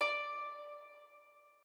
harp1_4.ogg